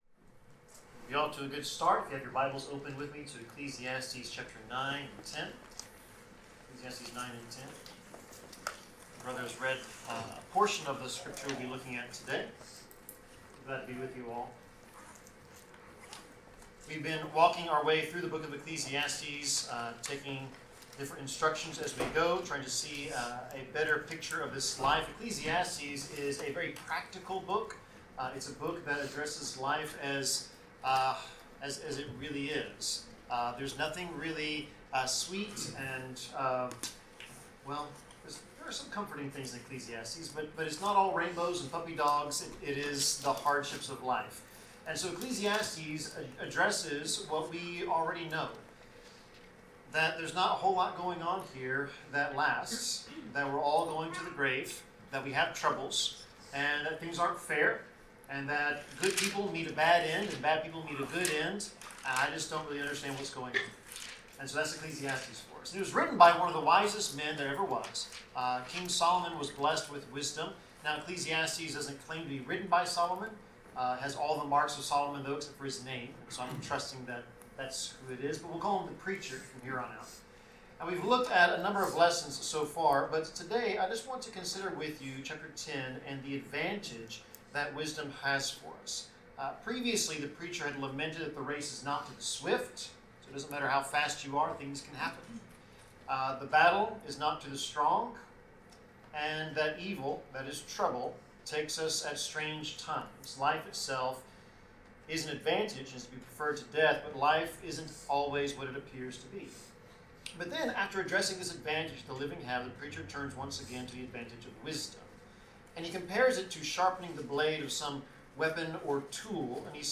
Passage: Ecclesiastes 9:13-10:20 Service Type: Sermon